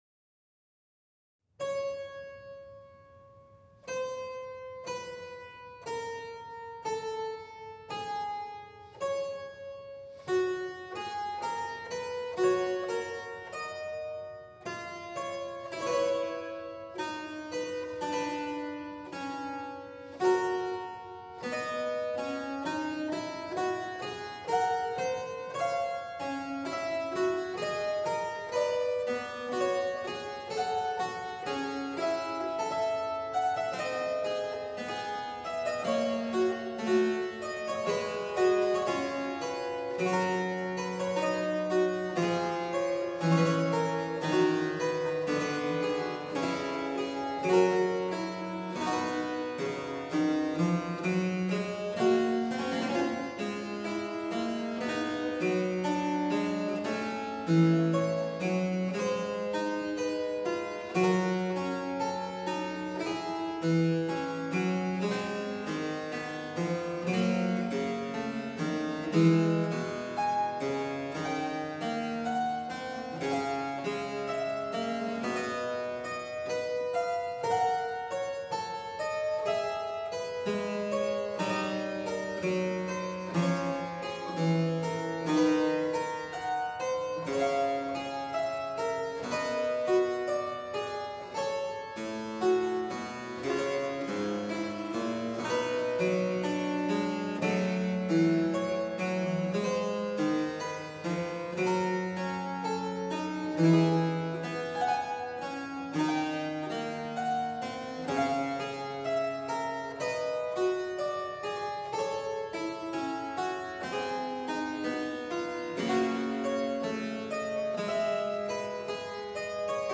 harpsichordist and conductor